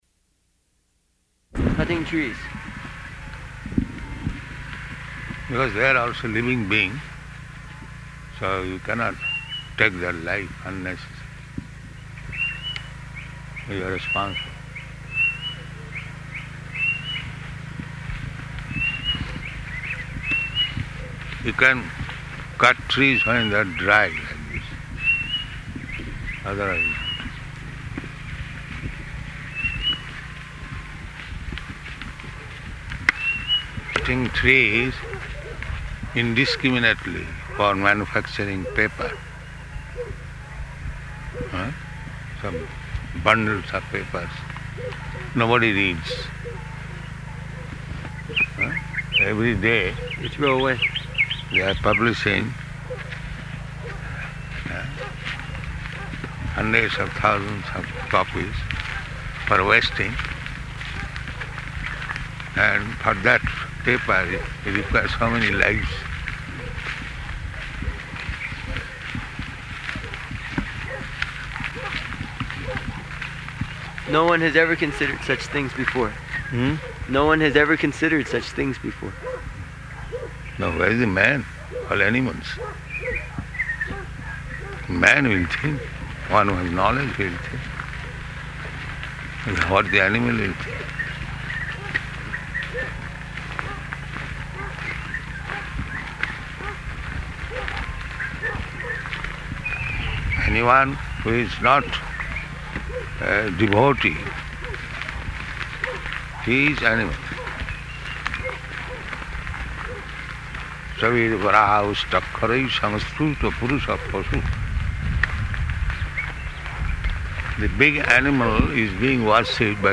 -- Type: Walk Dated: October 19th 1975 Location: Johannesburg Audio file